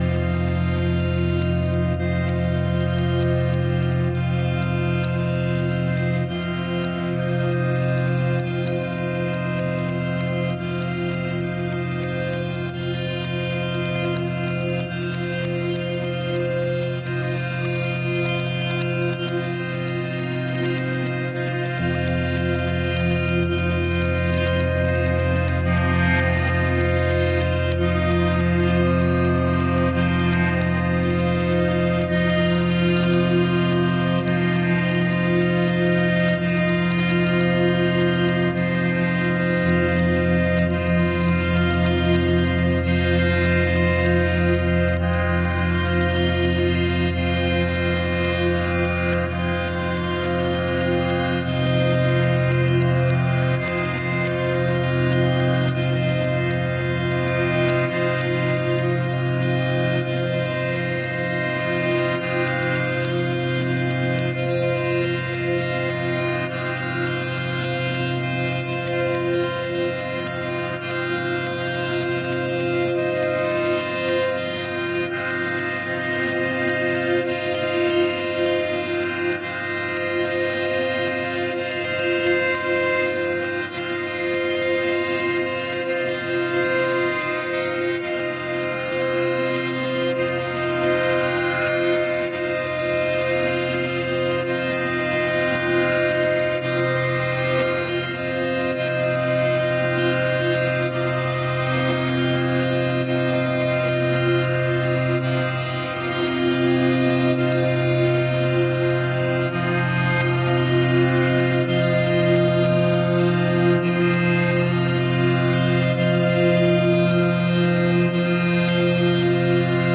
• Genre: Industrial / Esoteric / Dark Ambient